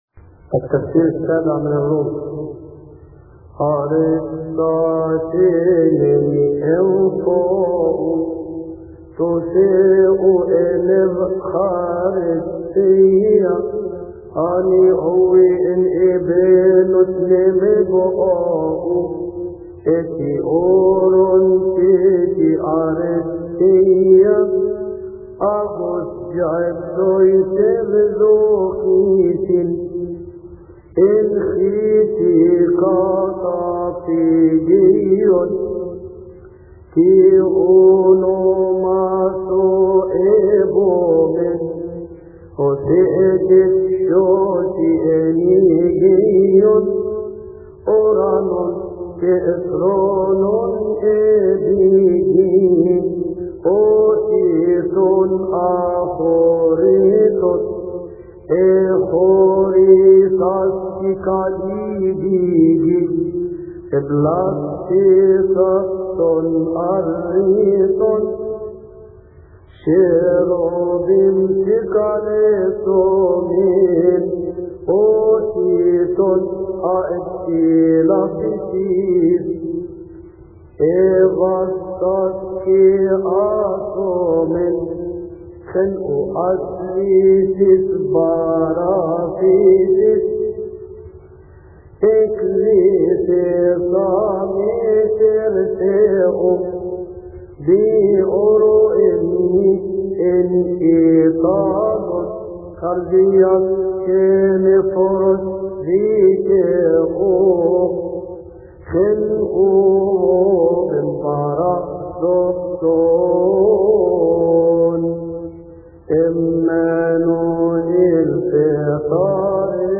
مكتبة الألحان
التفسير السابع من الرومي لثيؤطوكية السبت يصلي في تسبحة عشية أحاد شهر كيهك